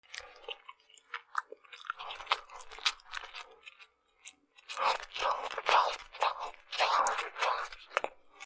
パンを食べる